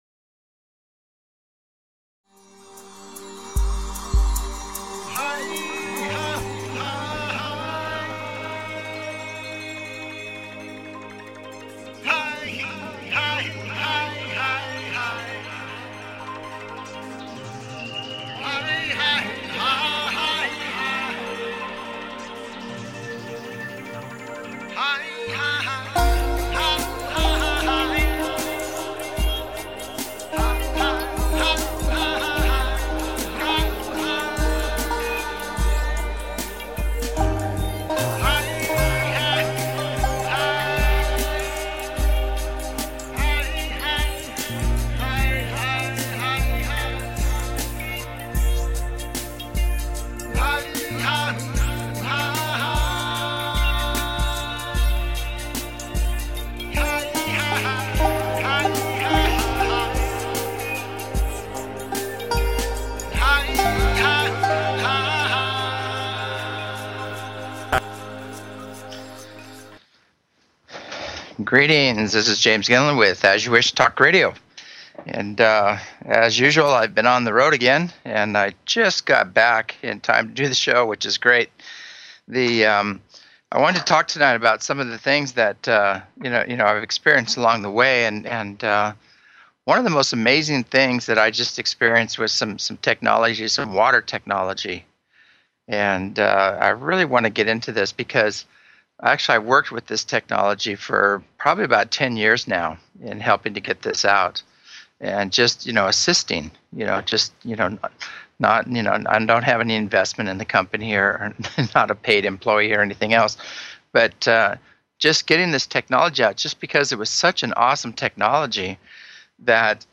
The energies are shifting rapidly and this brings up many questions for individuals. Call in with your questions.